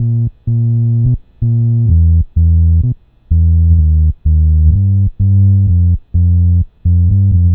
Track 10 - Bass 02.wav